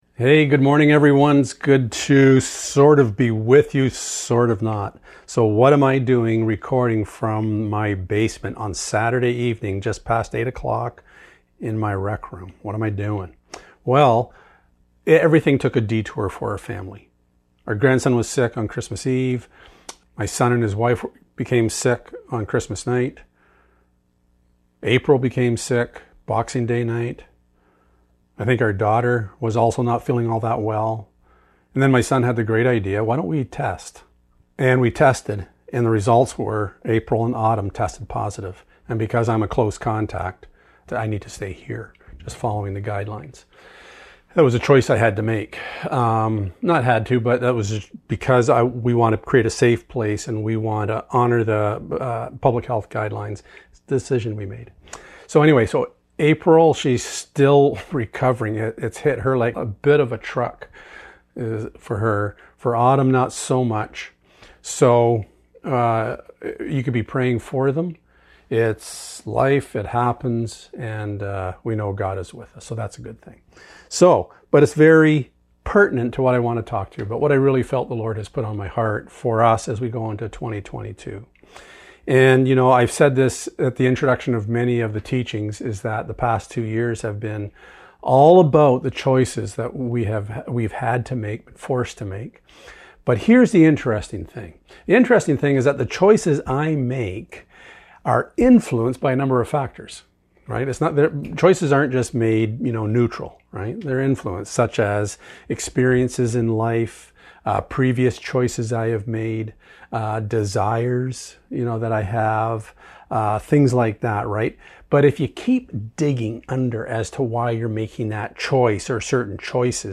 Exodus 33:11 Service Type: Sunday Morning Choices are placed before us everyday of our lives.